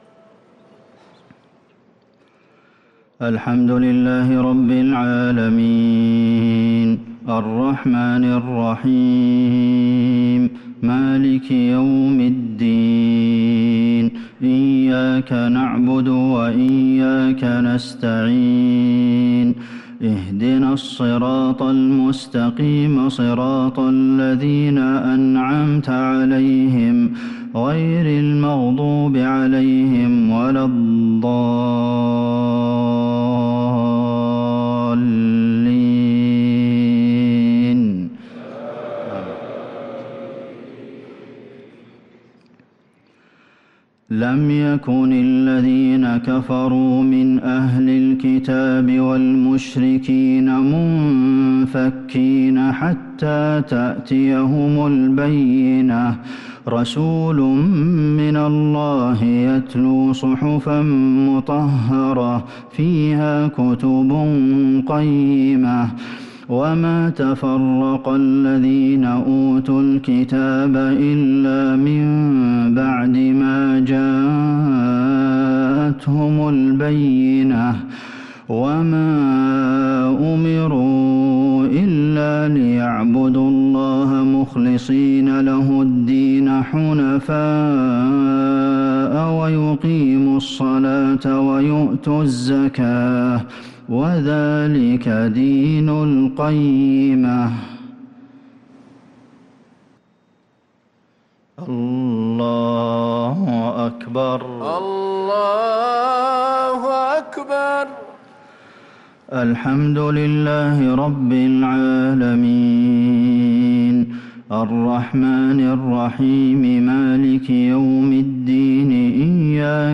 صلاة المغرب للقارئ عبدالمحسن القاسم 6 ذو الحجة 1443 هـ